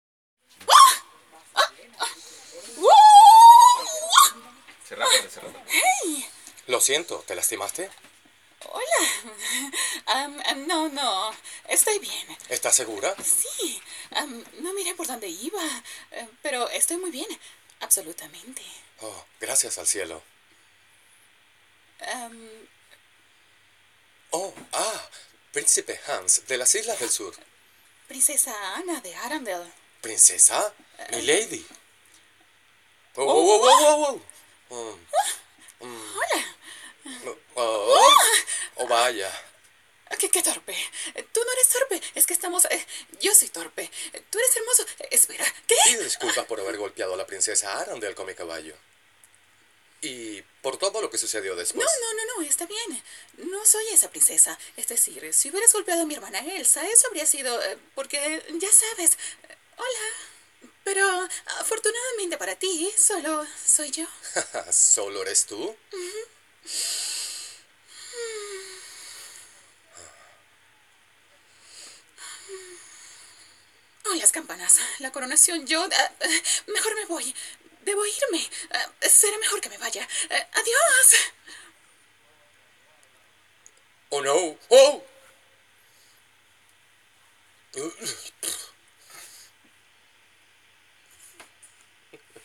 Velvety voice, soft, sweet and femenine but strong, jovial and emotional.
Sprechprobe: Sonstiges (Muttersprache):
Sprechprobe: Werbung (Muttersprache):
My voice is a particular tone that is difficult to achieve, it is sharp and elegant.